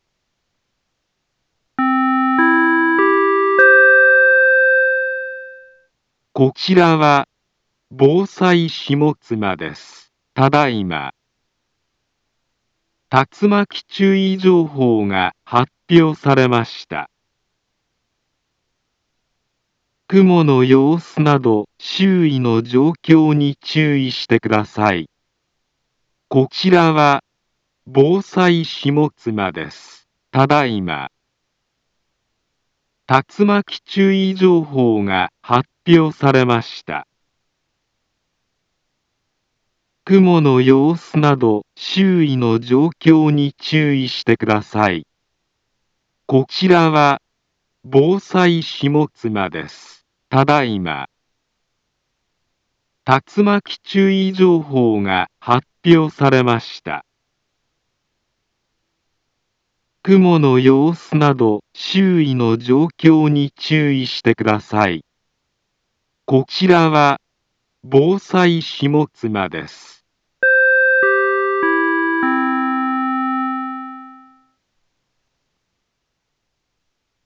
Back Home Ｊアラート情報 音声放送 再生 災害情報 カテゴリ：J-ALERT 登録日時：2022-06-03 16:15:15 インフォメーション：茨城県南部は、竜巻などの激しい突風が発生しやすい気象状況になっています。